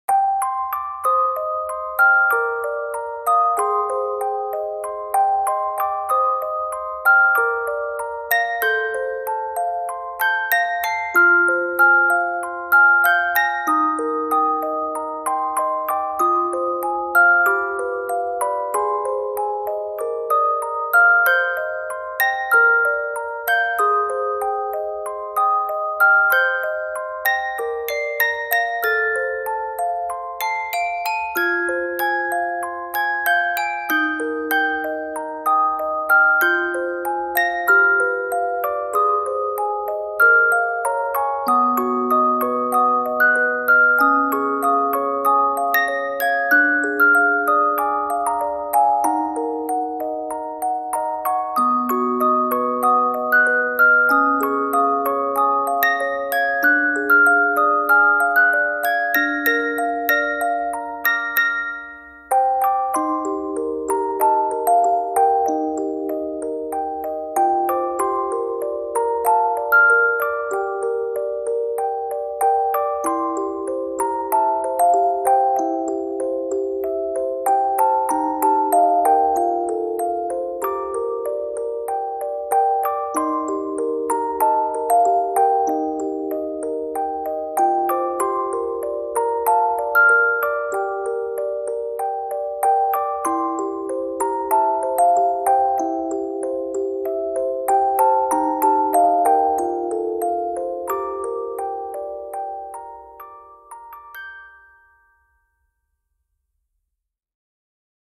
applause.mp3